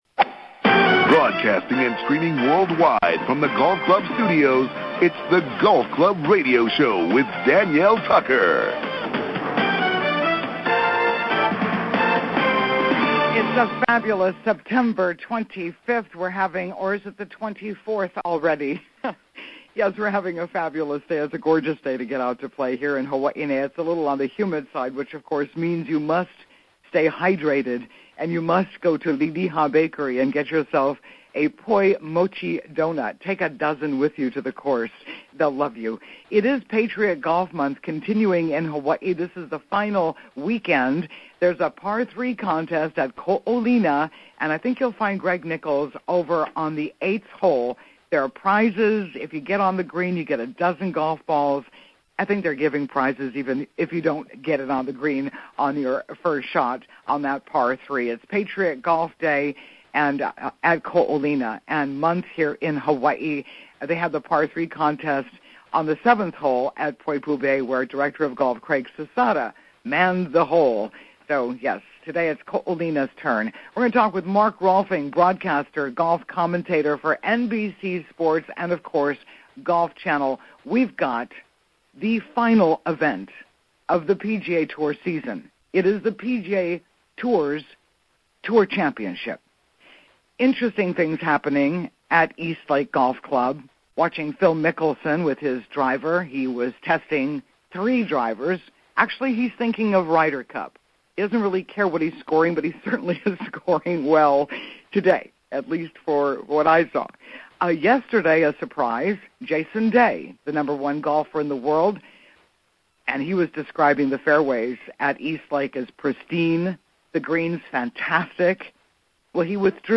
broadcasting state-wide talking to Hawaii's Golf Pros and across America sports shrinks, authors, mental coaches and PGA broadcasters.
Mark Rolfing: NBC Golf Commentator